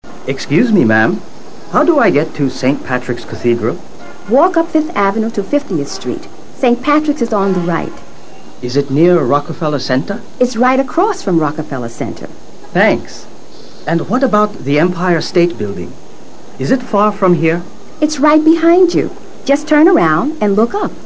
Un turista pregunta a una neoyorkina sobre la ubicación de algunos puntos turísticos.